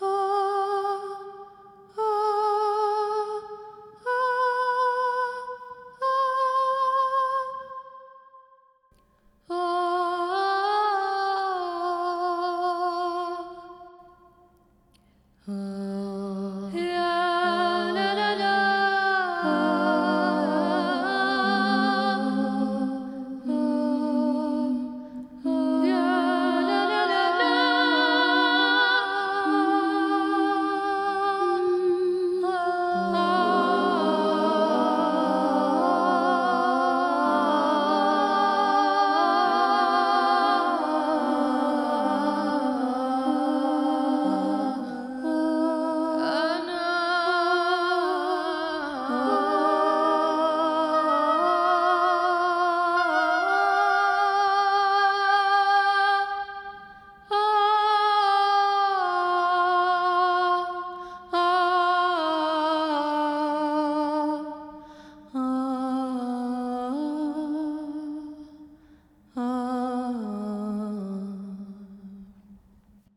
Muisc in Hijaz Scale